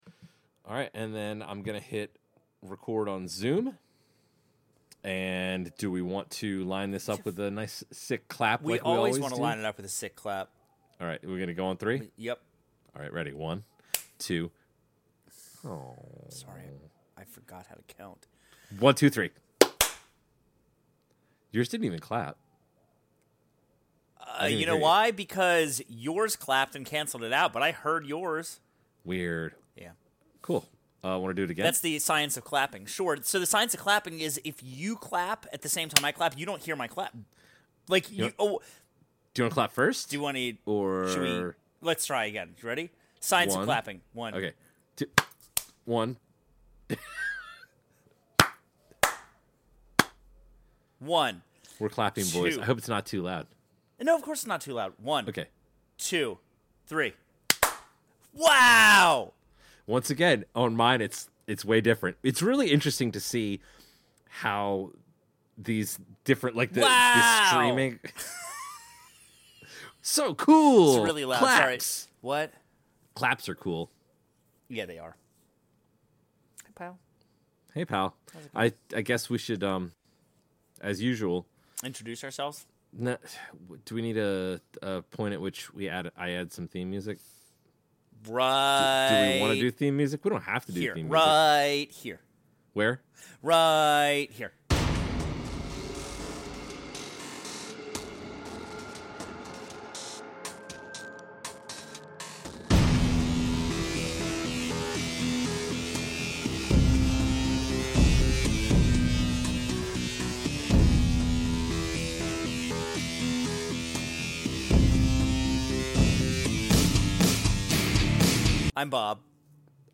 In this episode, our hosts talk about the second half of Doom Book 1: Knee Deep in the Dead.